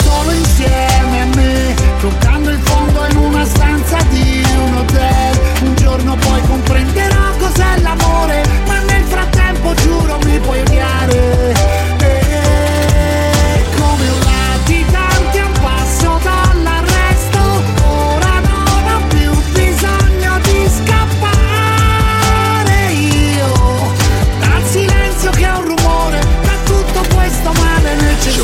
Genere: italiana,sanremo2026,pop.ballads,rap,hit